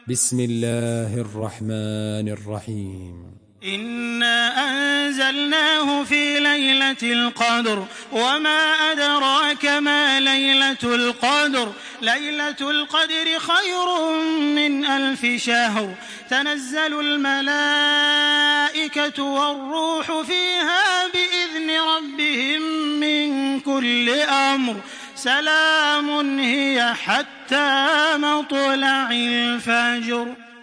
Surah Al-Qadr MP3 by Makkah Taraweeh 1426 in Hafs An Asim narration.
Murattal